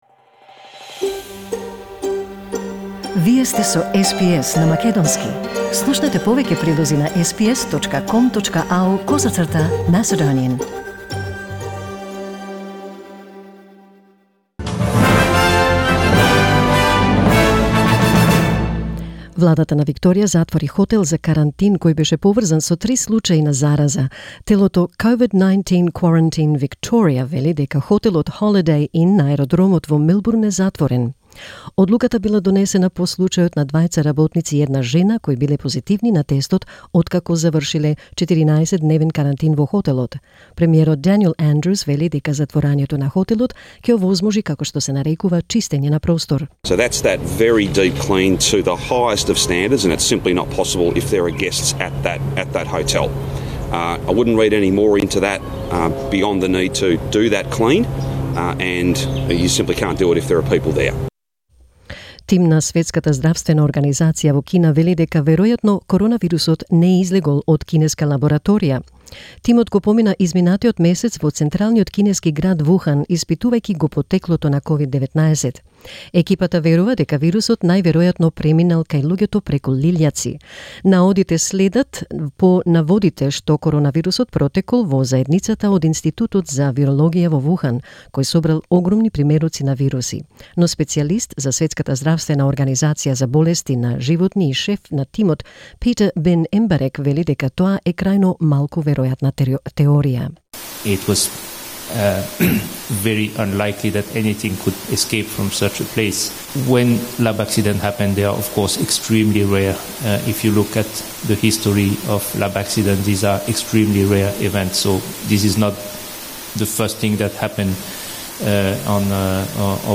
SBS Macedonian COVID-19 news update